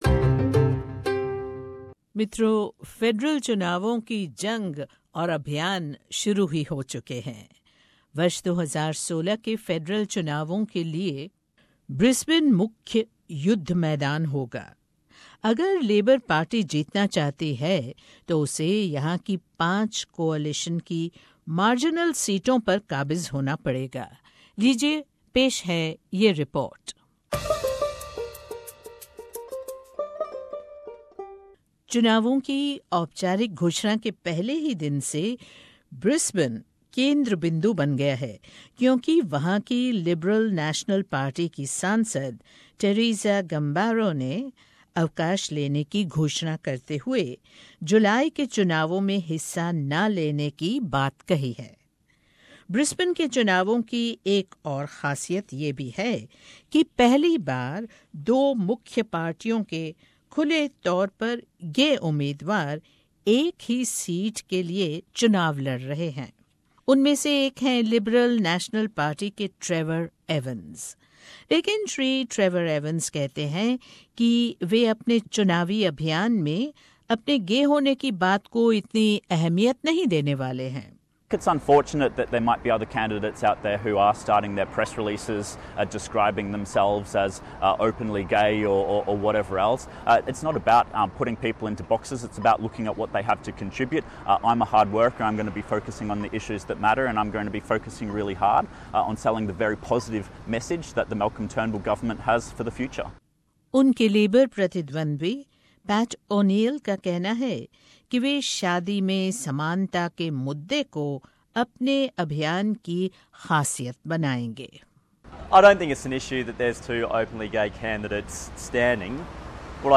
पेश है ये रिपोर्ट.